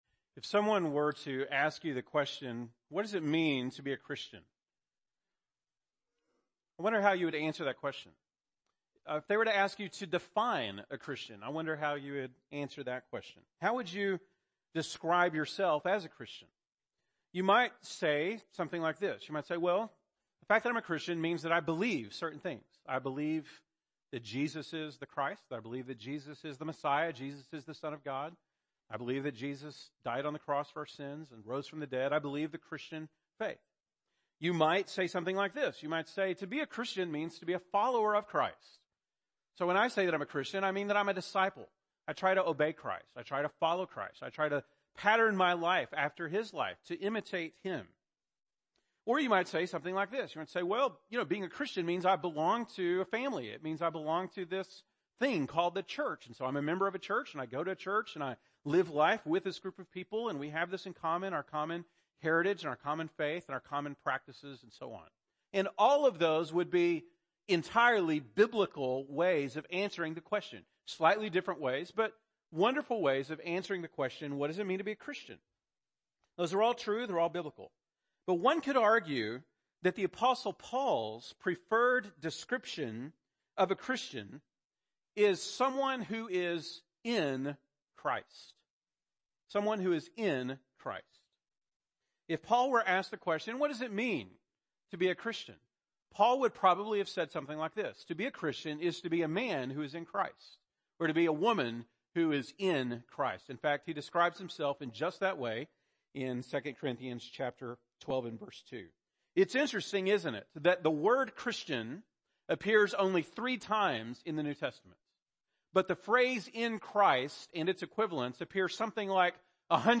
October 22, 2017 (Sunday Morning)